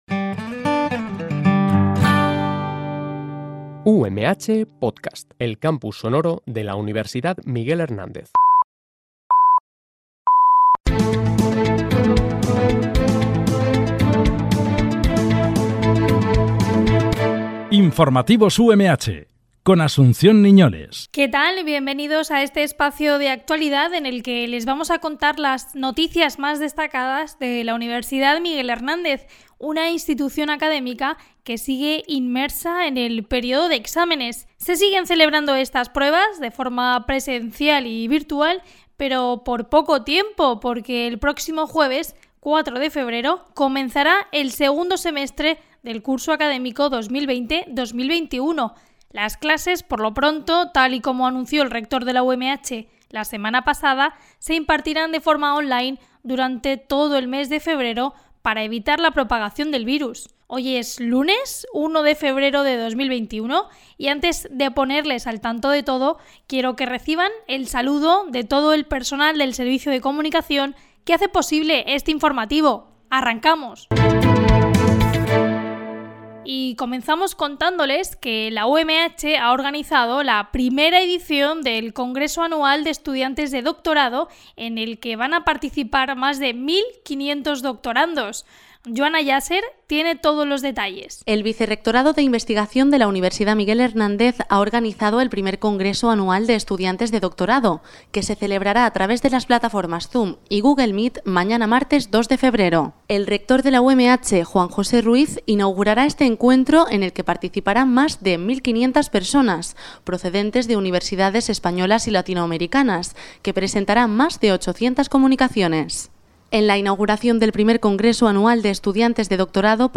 Este programa de noticias se emite de lunes a viernes, de 14.00 a 14.10 h